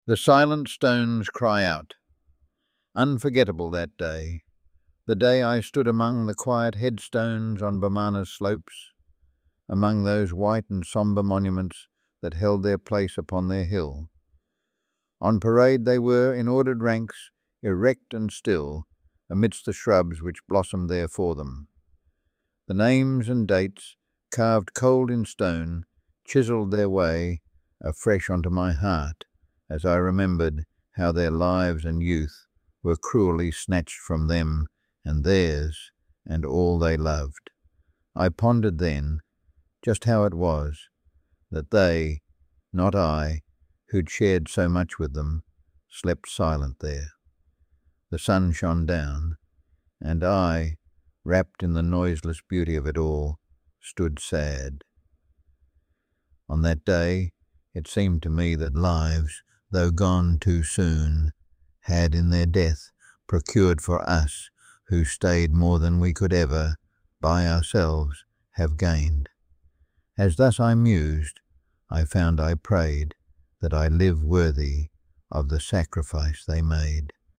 A re-creation of this poem with AI